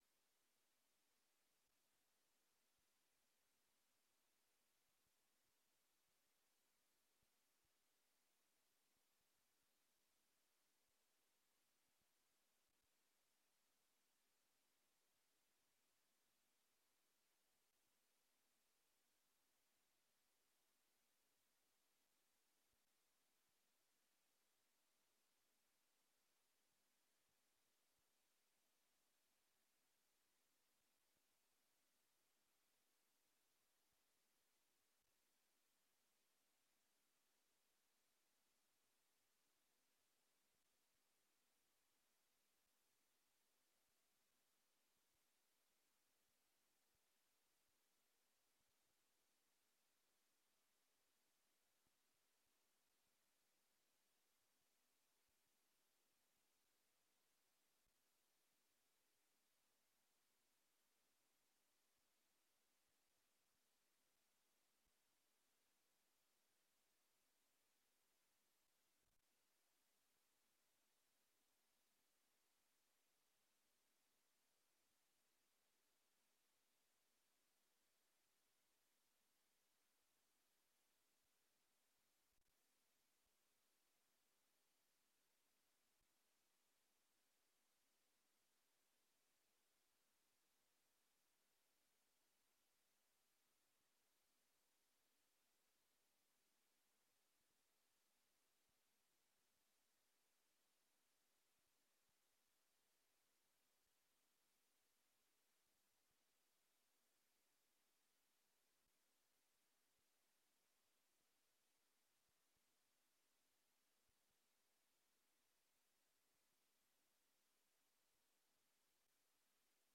Beeldvormende vergadering 22 juni 2023 19:30:00, Gemeente Dronten
Locatie: Raadzaal